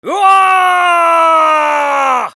兵士(戦い)
ボイス